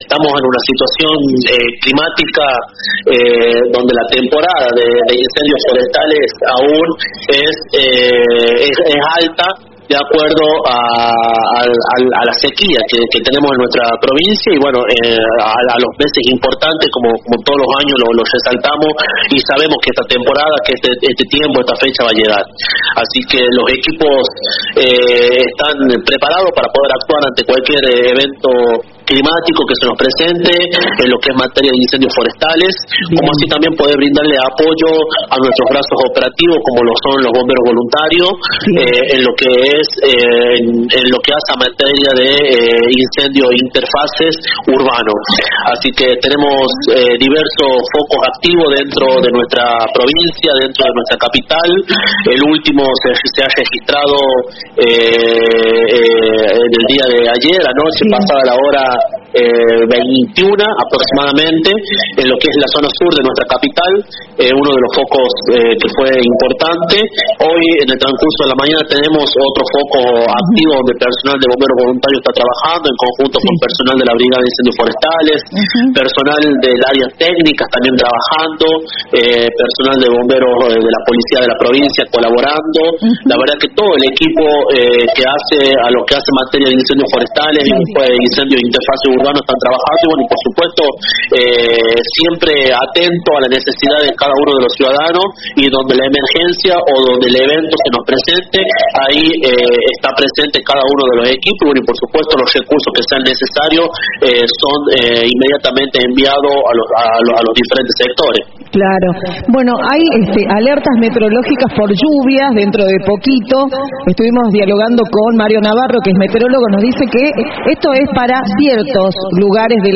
El director de Defensa Civil, Juan Pablo Moreno, explicó en diálogo con nuestros medios que el gobierno provincial, junto a Defensa Civil, Bomberos Voluntarios, dotaciones de bomberos asociados y el Ente Nacional de Manejo del Fuego, lleva adelante un operativo intensivo para contener las llamas y evitar la propagación a zonas pobladas.